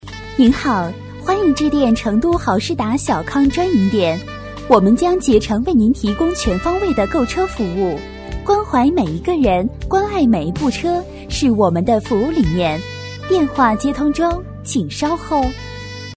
女声配音
彩铃女国71